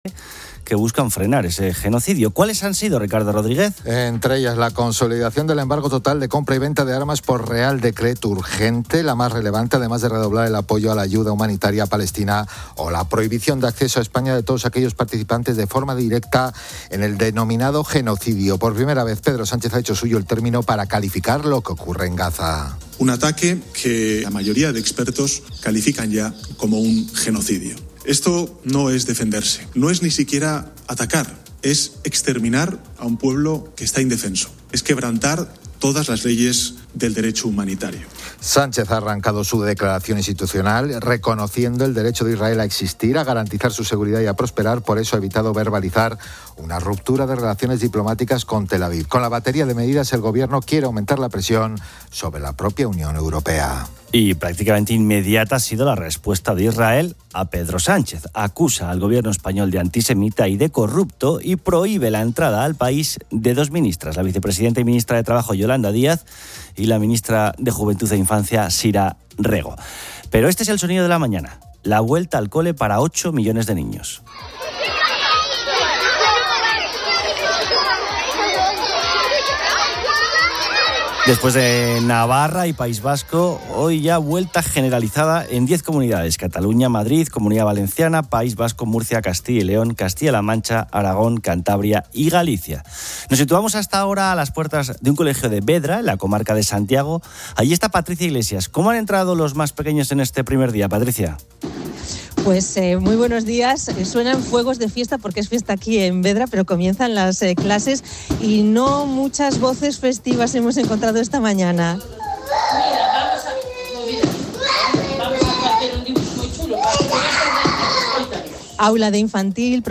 El audio cubre varias noticias y segmentos de opinión.
Un segmento de radio interactivo pregunta a los oyentes qué cosas no saben hacer bien siendo adultos, como atarse los cordones o pedir comida sin ayuda.